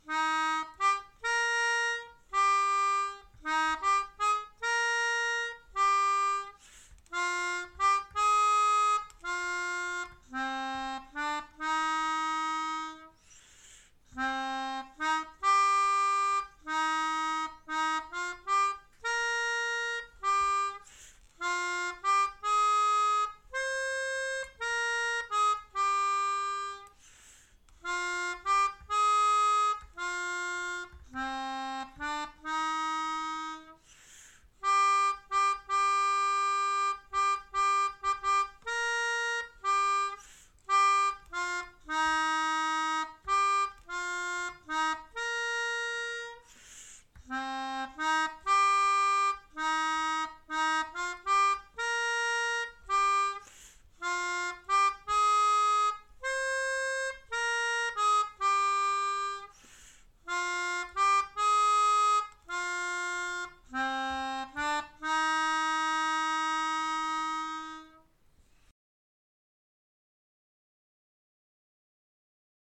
Melodica / Pianica / Airboard